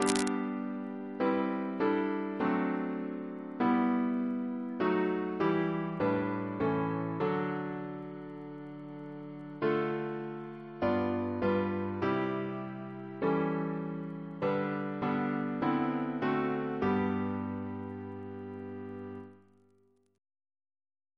Double chant in G Composer: Raymond Lewis Reference psalters: CWP: 112